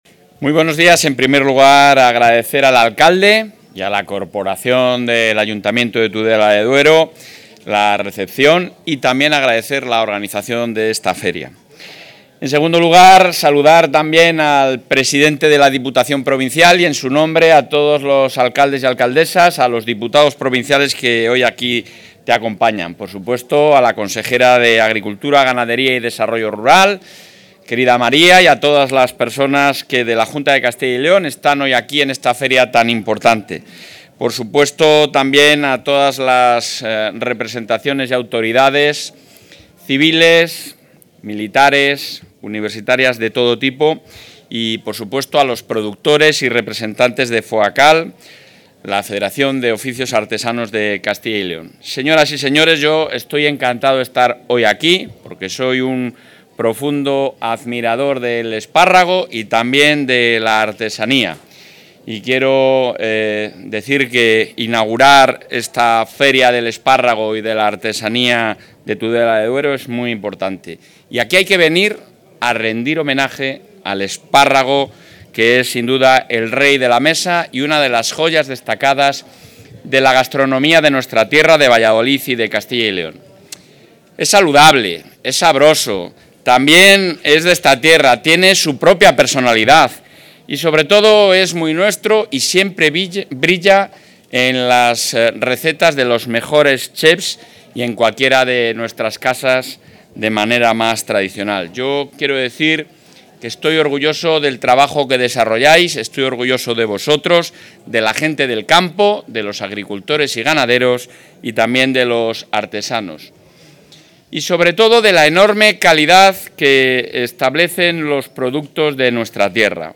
Intervención del presidente de la Junta.
El presidente de la Junta de Castilla y León, Alfonso Fernández Mañueco, ha visitado hoy la 41ª Feria de Exaltación del Espárrago y la Artesanía de Tudela de Duero, donde ha anunciado que el Ejecutivo autonómico está trabajando en una nueva convocatoria de ayudas para la incorporación de jóvenes agricultores y la modernización de explotaciones. Esta nueva línea contará con mejoras sustanciales orientadas a simplificar la gestión, agilizar los procedimientos y reducir la carga administrativa para los solicitantes.